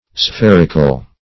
\Spher"i*cle\